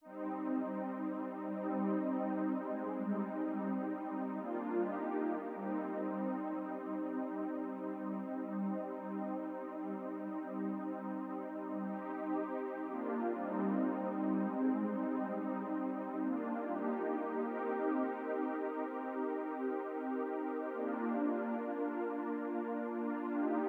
11 pad A.wav